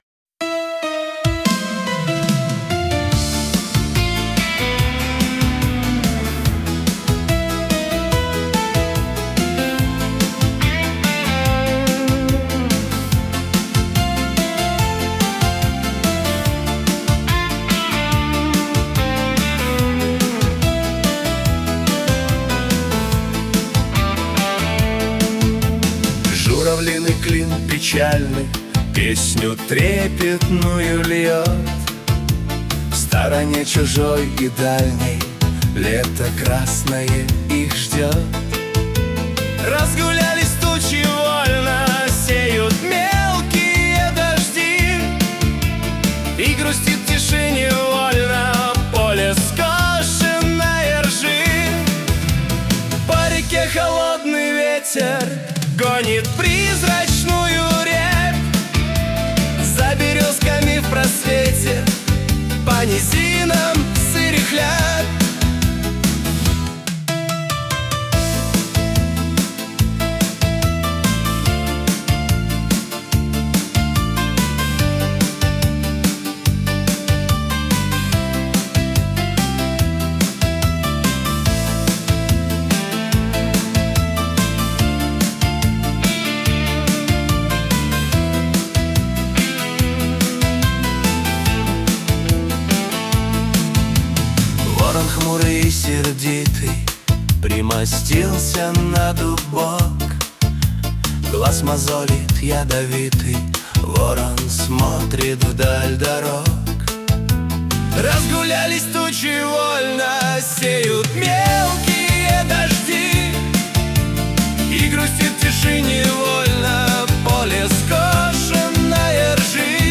Стихотворение ЕЛИ